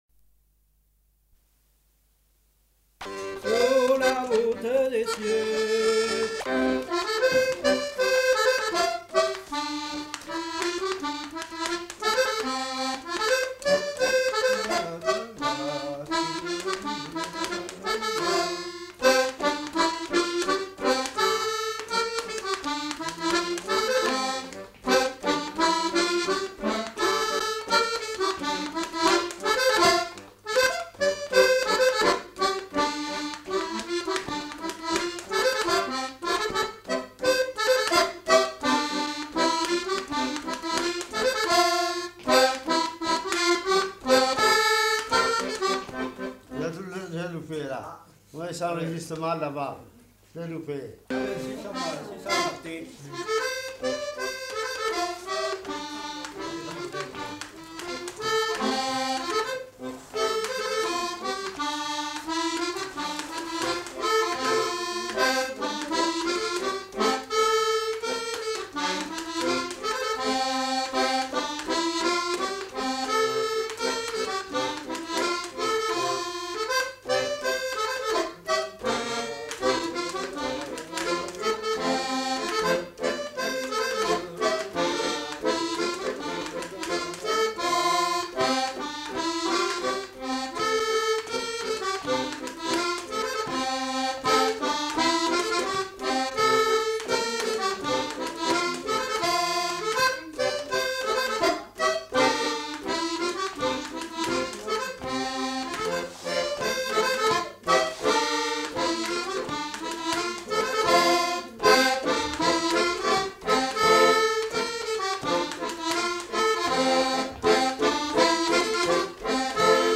Aire culturelle : Auvergne
Lieu : Peschadoires
Genre : morceau instrumental
Instrument de musique : accordéon
Danse : scottish